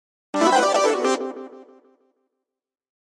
游戏音效 " FX165
描述：爆炸哔哔踢游戏gameound点击levelUp冒险哔哔sfx应用程序启动点击
Tag: 爆炸 单击 冒险 游戏 应用 蜂鸣 点击的LevelUp 启动 gamesound 哔哔声 SFX